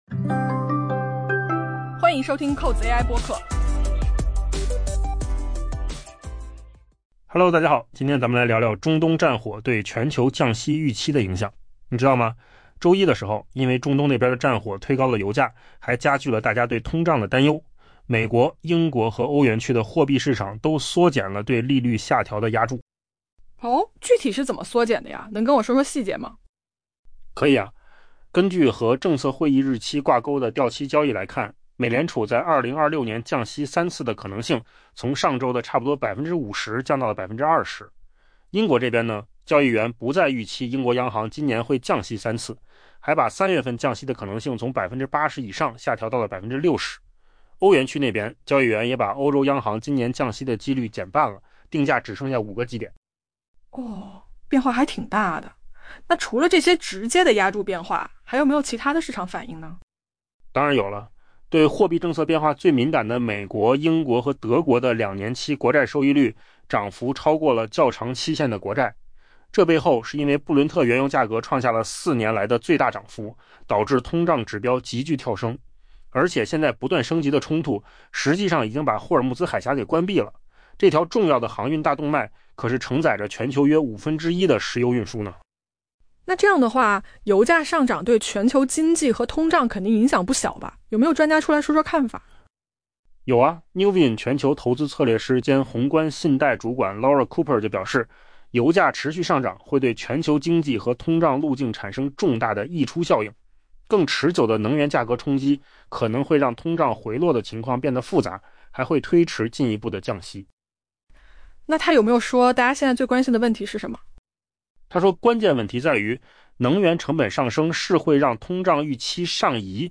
AI 播客：换个方式听新闻 下载 mp3 音频由扣子空间生成 周一，随着中东战火推高油价并煽动通胀担忧，美国、英国和欧元区的货币市场缩减了对利率下调的押注。